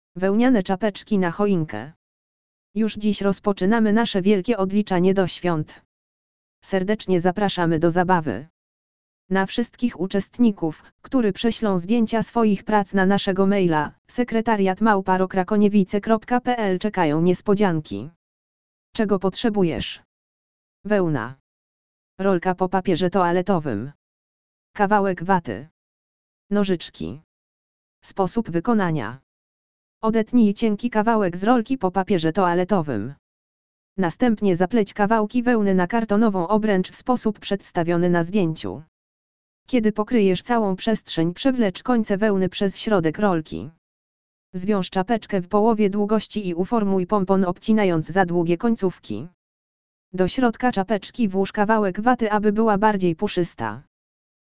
audio_lektor_welniane_czapeczki_na_choinke.mp3